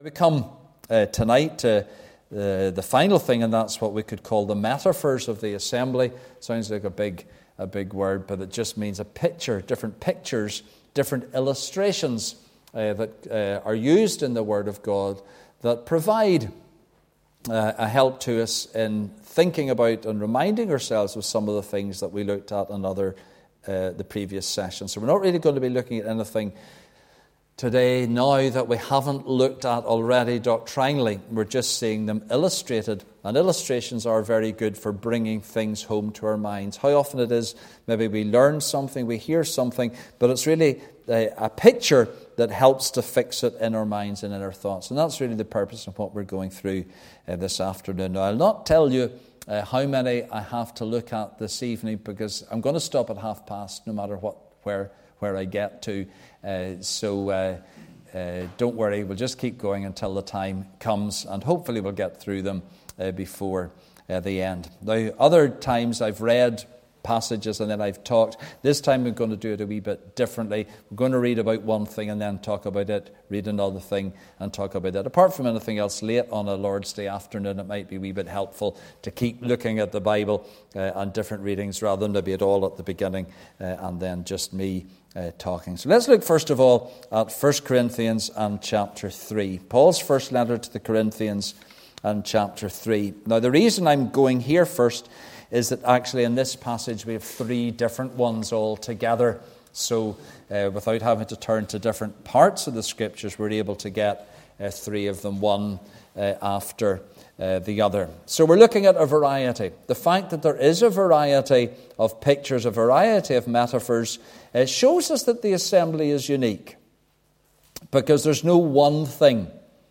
Message preached Sunday 28th January 2024